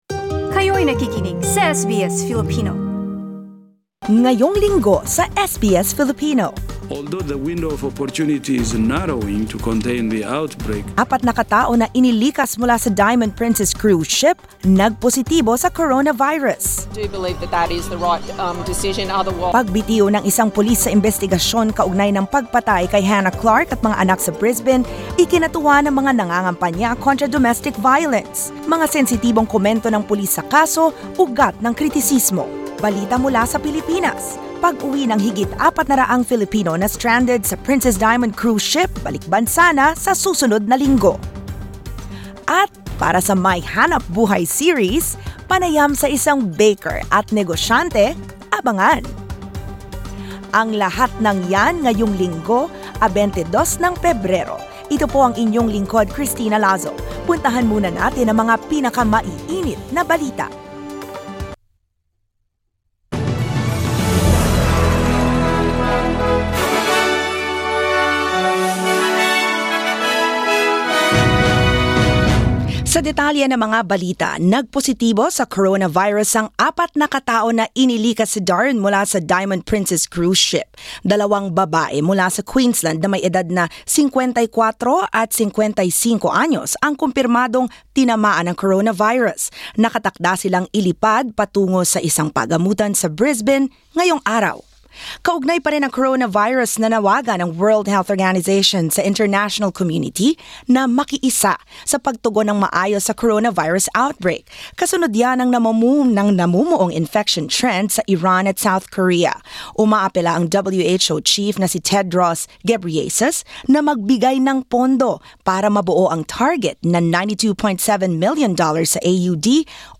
SBS News in Filipino, Saturday 22 February